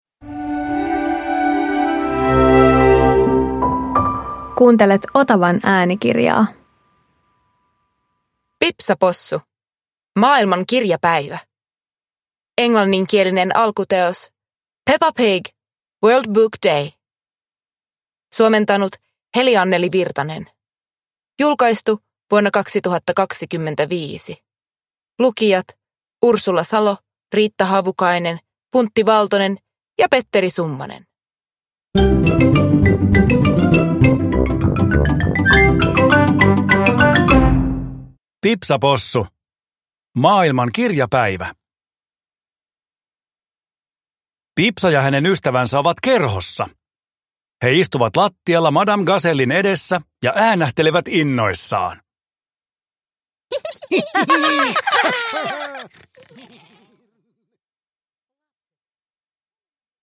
Pipsa Possu - Maailman kirjapäivä – Ljudbok